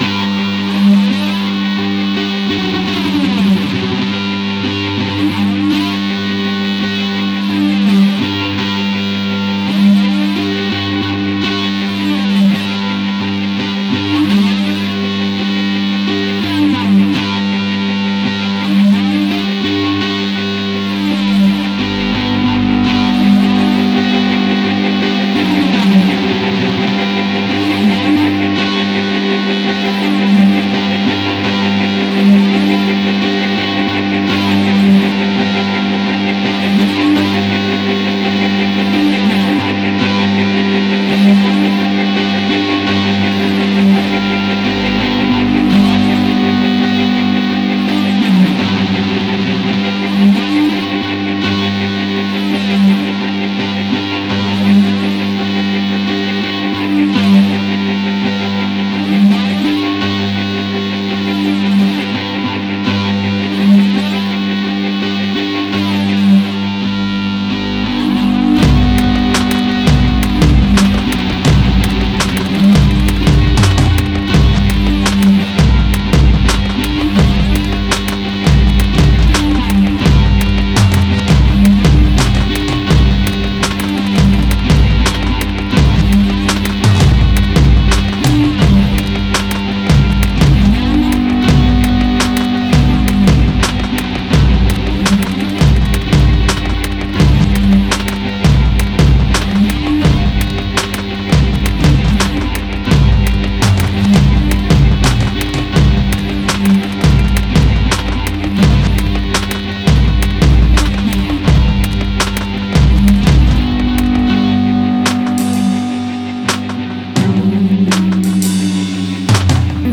modern and simplistic instrumental industrial music